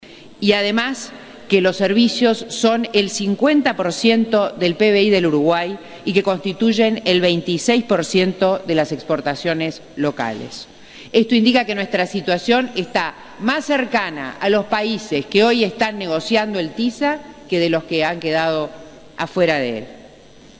Interpelación en el Senado